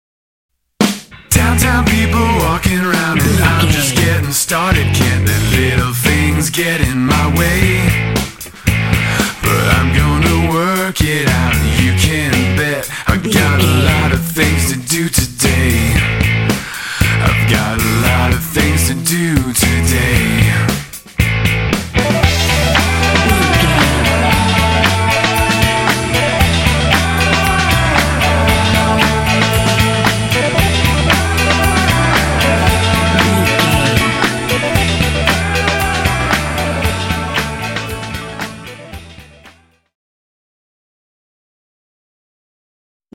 Aeolian/Minor
bouncy
happy
groovy
motivational
drums
bass guitar
synthesiser
vocals
80s
rock
hard rock
classic rock